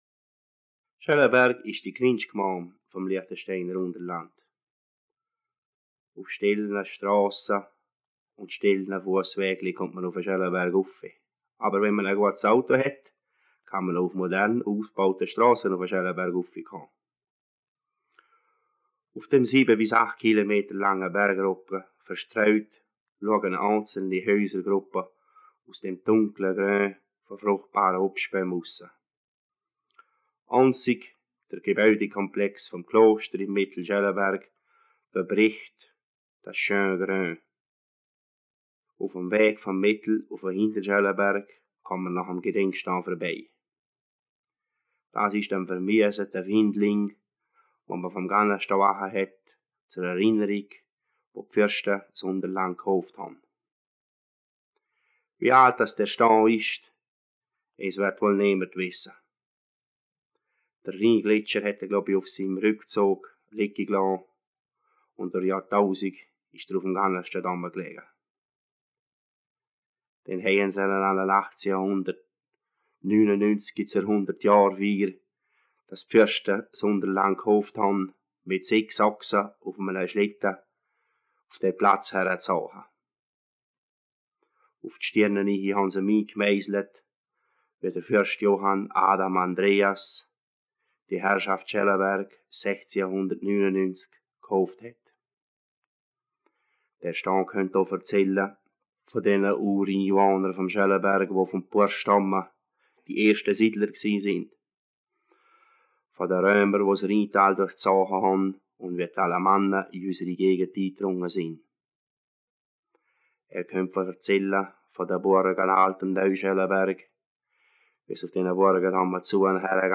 Liechtensteiner Mundarten 1960 (Gemeinden)
Geschichten in Mundart